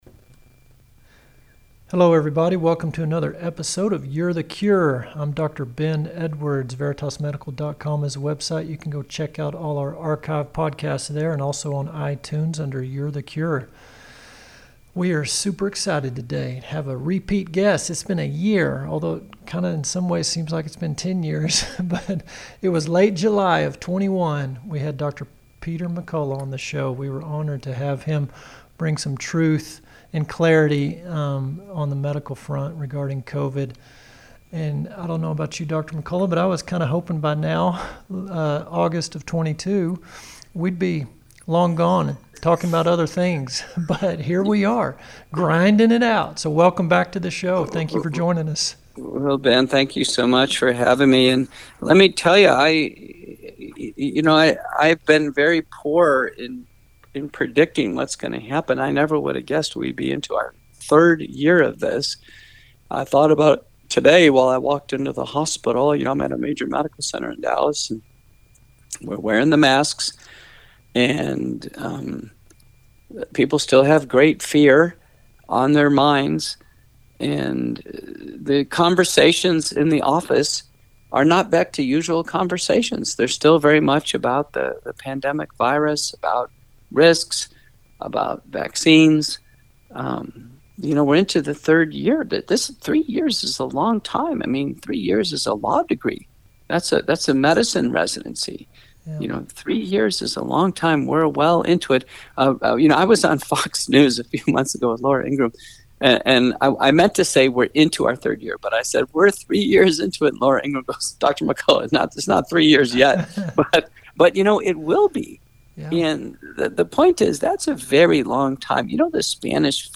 interviews Dr. Peter McCullough